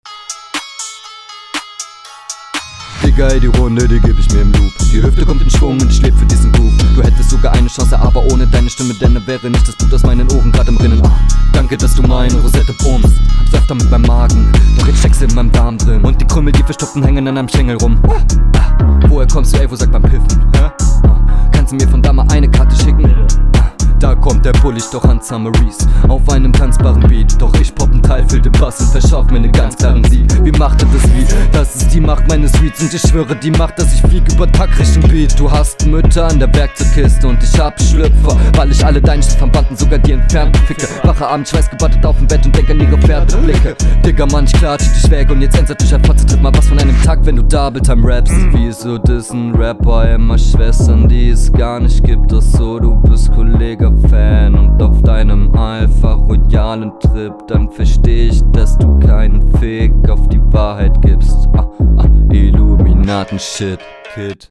Flow und Text finde ich eigentlich auch ganz fresh, aber kommt nicht an die Vorgabe.
Flow deutlich unterlegen und die Konter reißen es auch nicht raus.